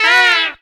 HARM FALL.wav